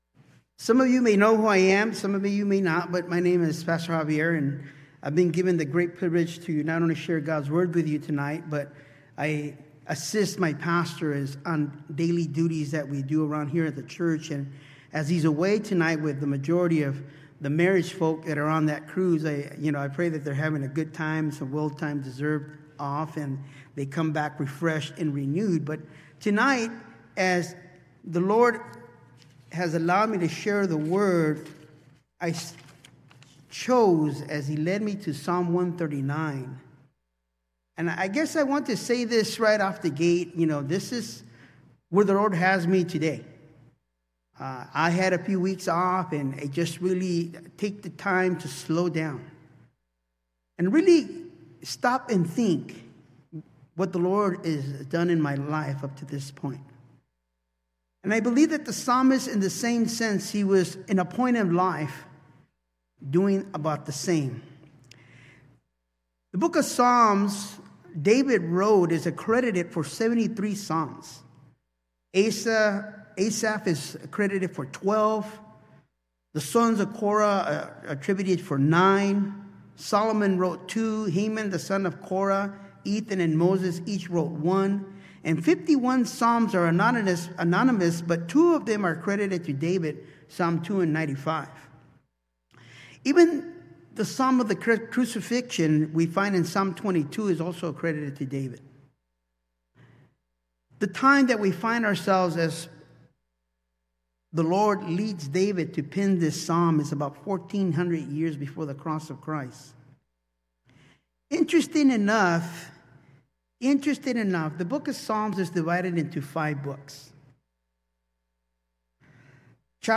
A message from the series "Guest Speaker."